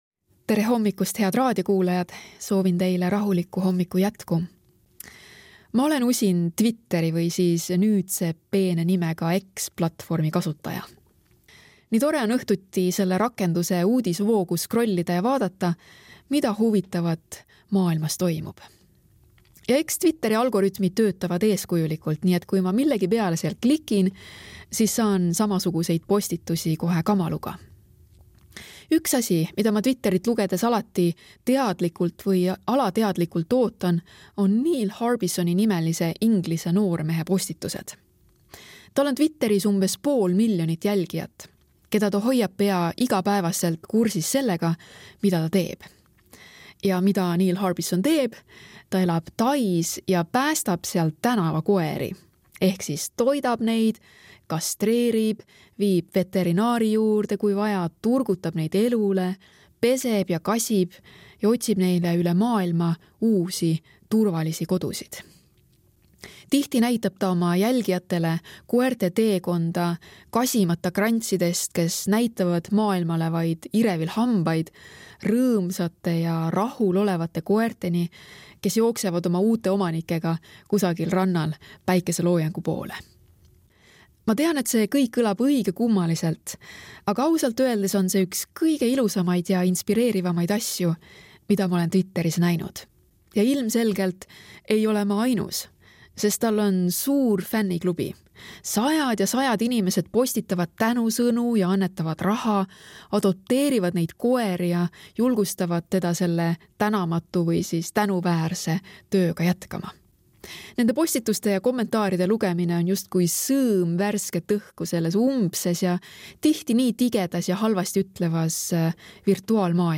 Hommikupalvused